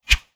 Close Combat Swing Sound 39.wav